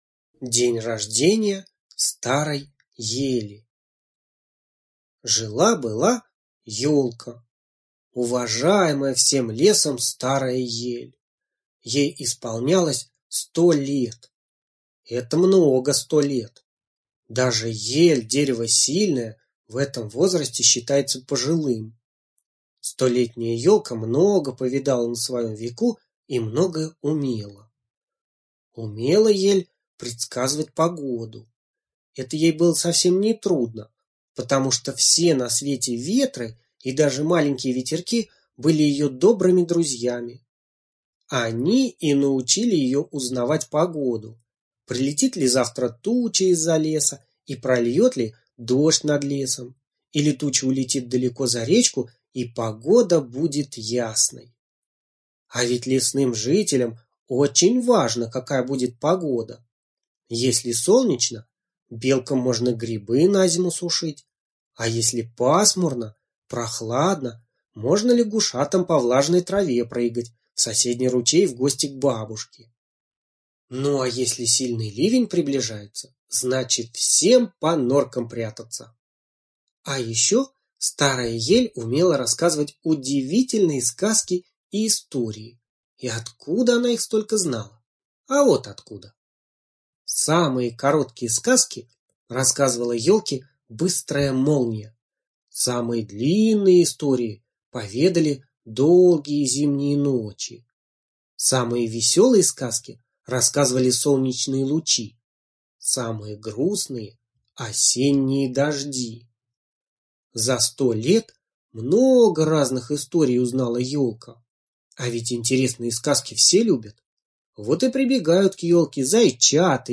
Слушайте День рождения старой ели - аудиосказка Абрамцевой Н. Как звери в лесу решили устроить праздник для старой и уважаемой ели.